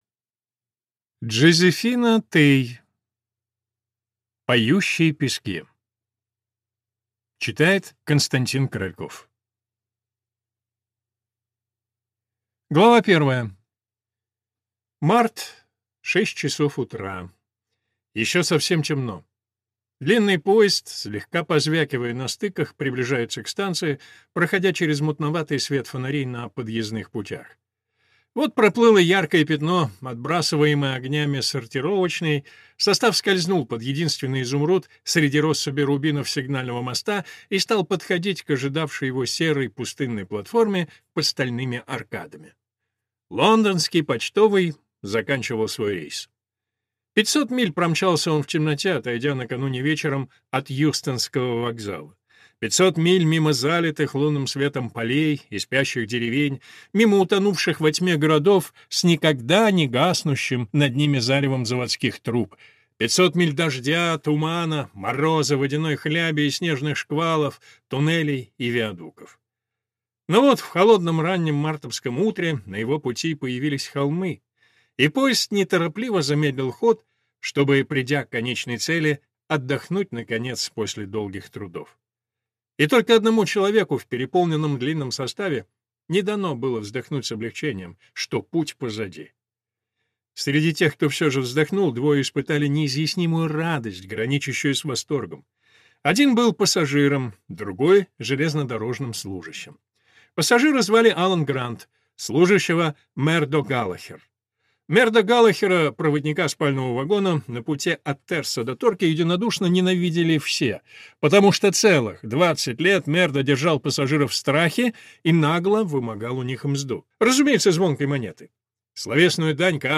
Аудиокнига Поющие пески | Библиотека аудиокниг